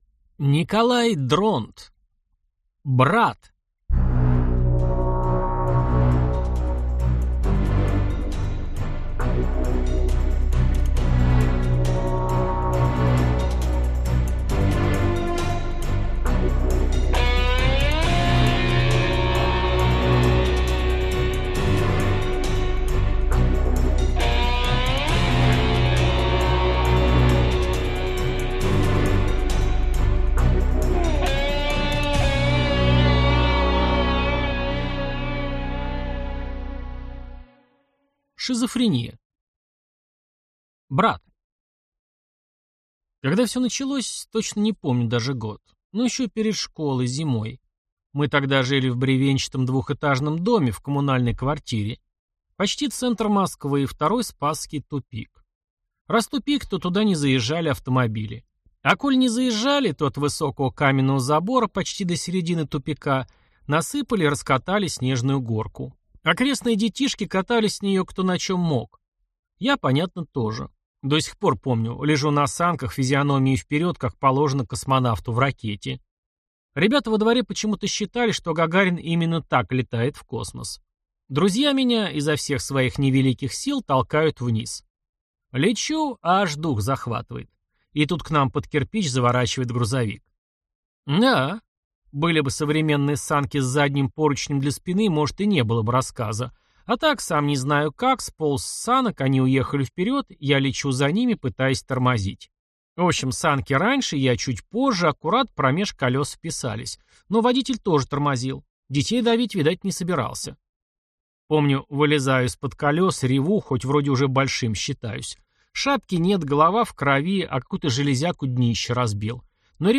Аудиокнига Брат | Библиотека аудиокниг